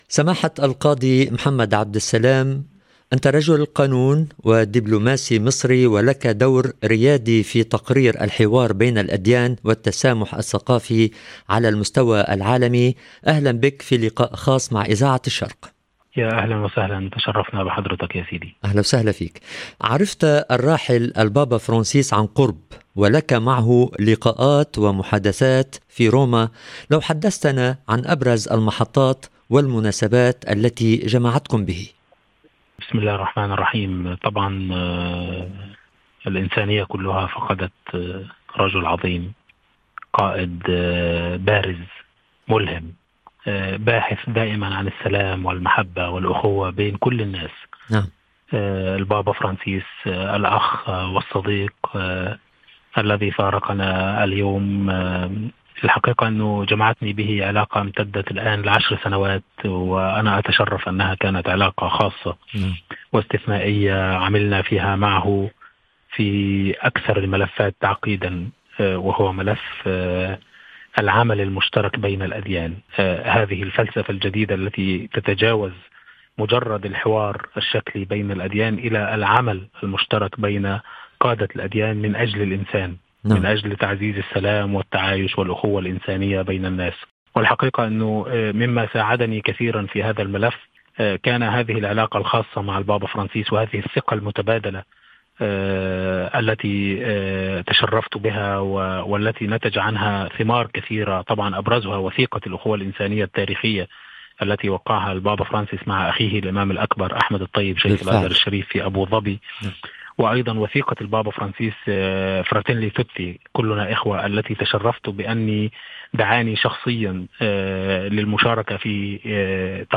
عن وثيقة الأخوة الإنسانية والأسرار التي كانت وراءها ودور البابا في وثيقة أخلاقيات الذكاء الاصطناعي كان لنا هذا اللقاء